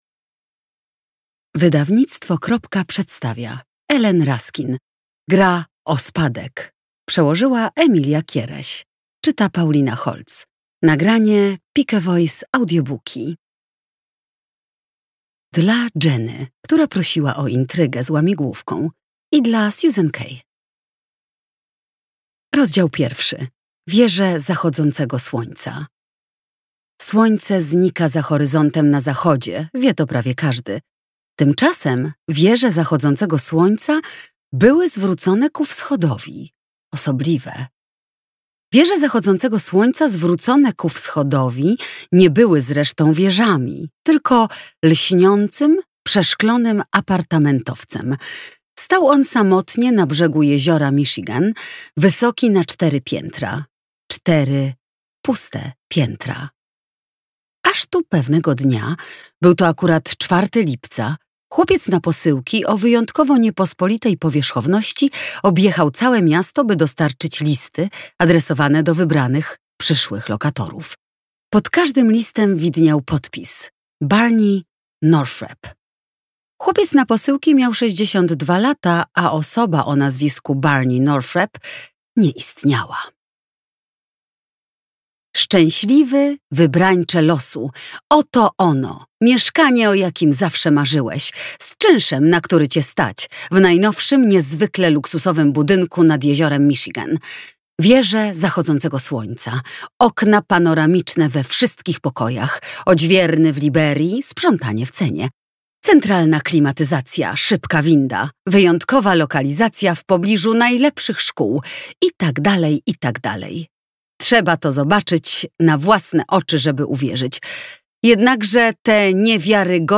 Wysłuchaj fragmentu audiobooka , czyta Paulina Holtz